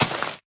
Sound / Minecraft / dig / grass4